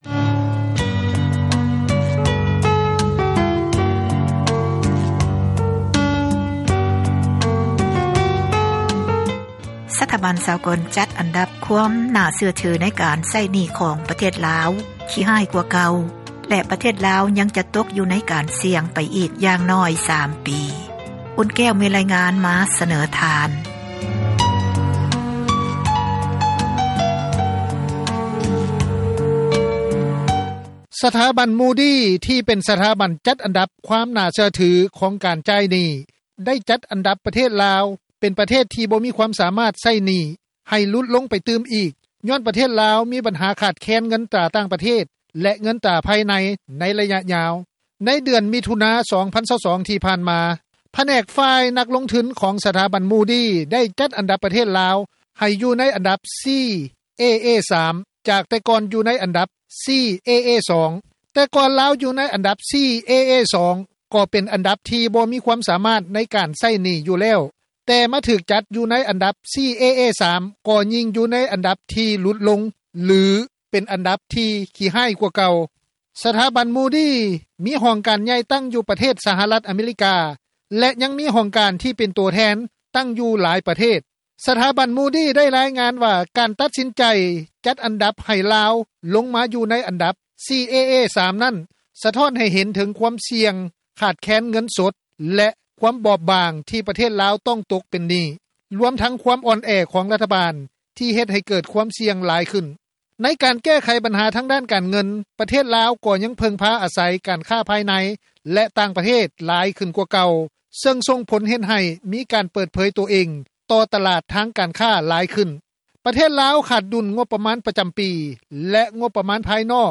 ກ່ຽວກັບເຣື່ອງນີ້ ຊາວນະຄອນຫຼວງວຽງຈັນ ຜູ້ນຶ່ງ ກ່າວຕໍ່ ວິທຍຸເອເຊັຽເສຣີ ວ່າ:
ສ່ວນຊາວລາວອີກຜູ້ນຶ່ງ ໃນນະຄອນຫຼວງວຽງຈັນ ກ່າວວ່າ: